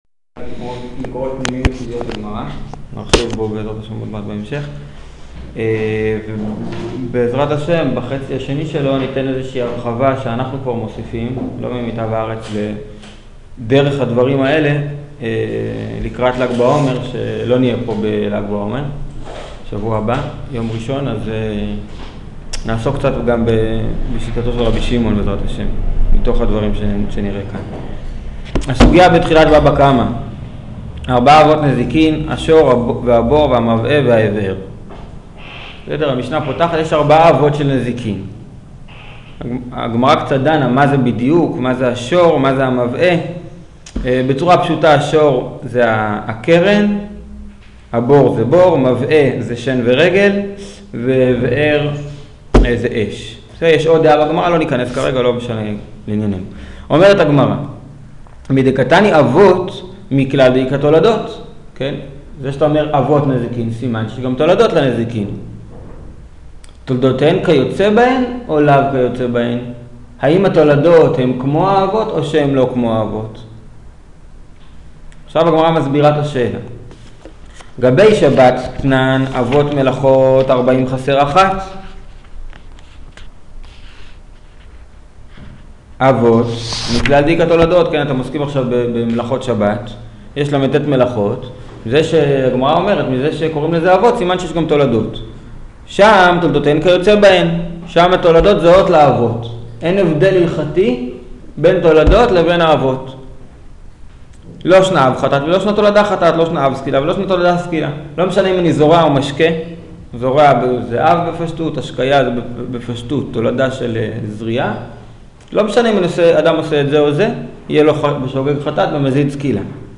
תולדותיהן של אבות נזיקין (בבא קמא ב) - מקורות פנימיים מקבילים 92 דקות 84.4 MB 0:00 0:00 פלוס 10 שניות מינוס 10 שניות 1.0 x מהירות השמעה 1.0 x 0.5 x 1 x 1.5 x 2 x 2.5 x 3 x הורד את קובץ השמע האזנה לשיעור Howler.js